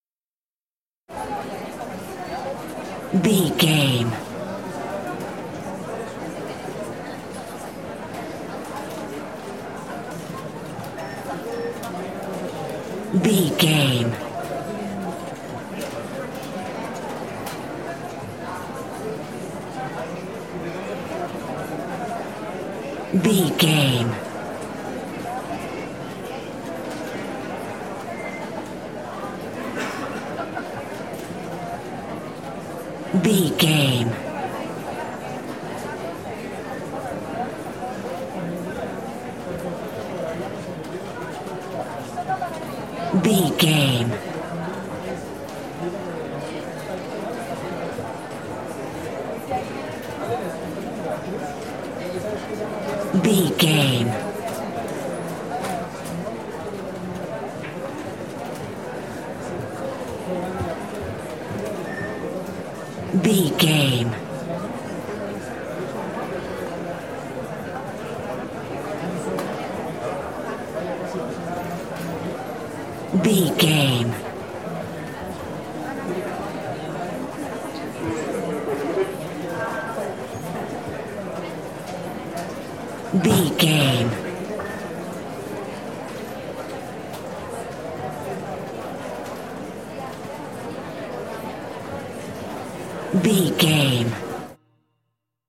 Telemarketing office large crowd
Sound Effects
chaotic
ambience